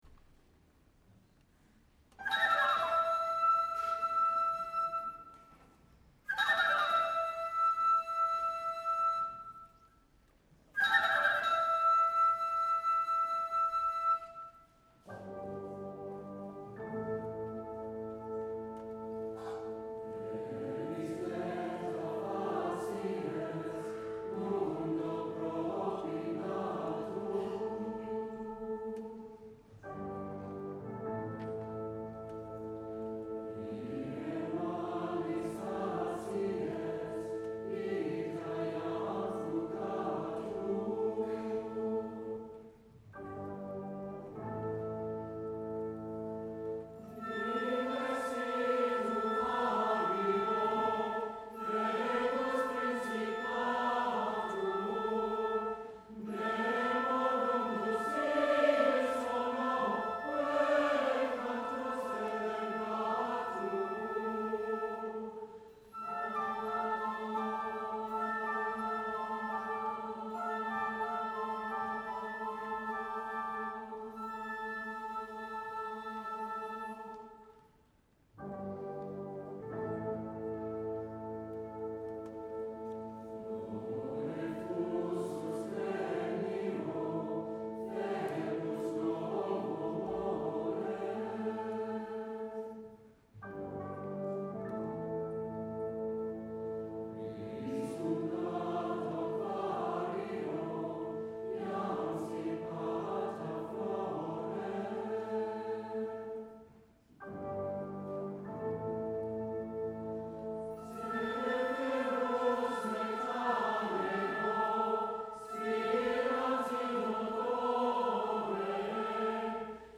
Saturday evening performance.